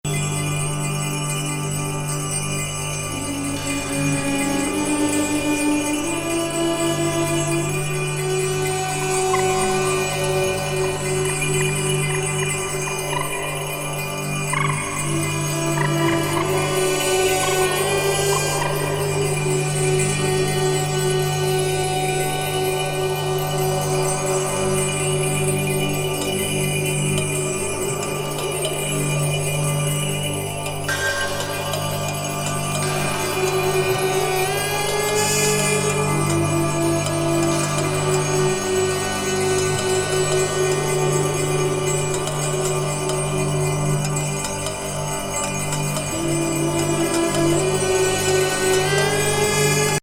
幽玄の北欧遠景ジャズ